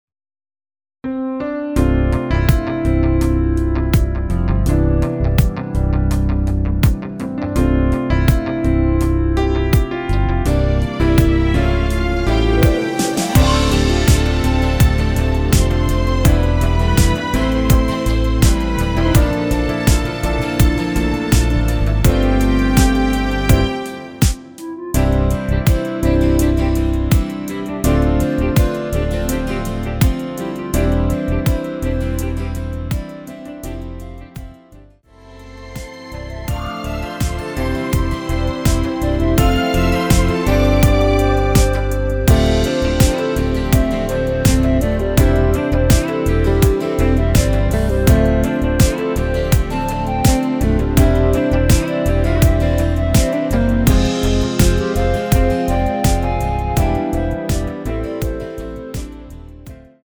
원키에서(-3)내린 멜로디 포함된 (1절+후렴)MR입니다.
◈ 곡명 옆 (-1)은 반음 내림, (+1)은 반음 올림 입니다.
앞부분30초, 뒷부분30초씩 편집해서 올려 드리고 있습니다.
중간에 음이 끈어지고 다시 나오는 이유는